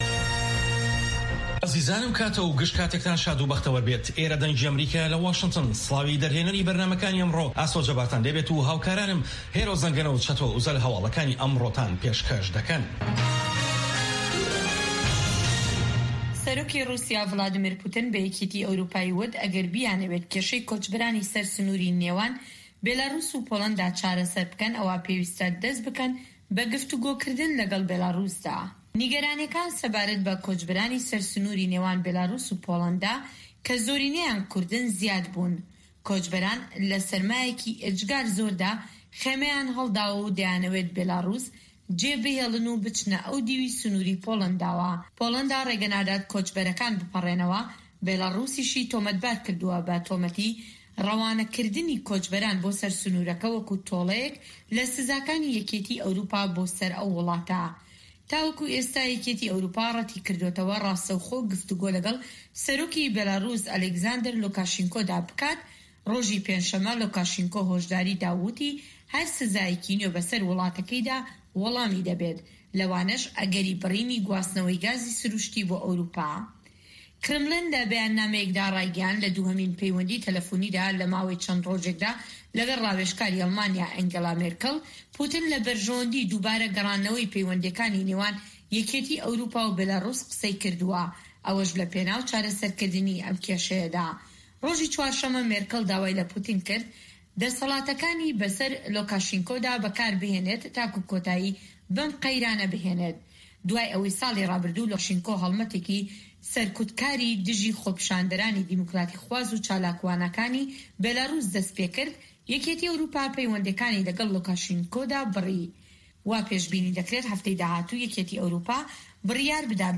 هه‌واڵه‌کان، ڕاپۆرت ، وتووێژ